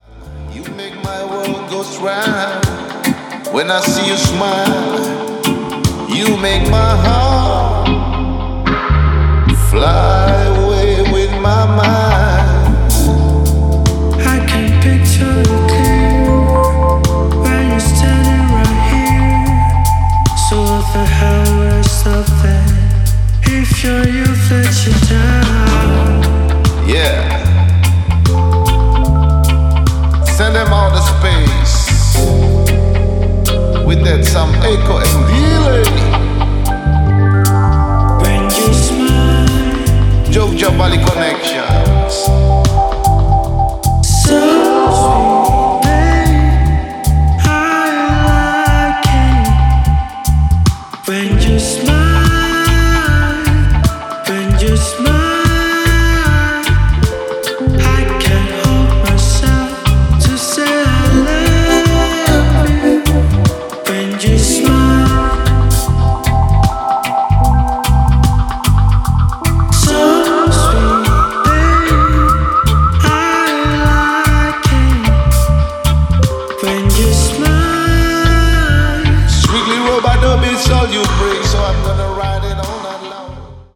Dub Remix
gitaris
seruling dan ukulele